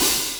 Open Hats
OpenHH Koopa 2.wav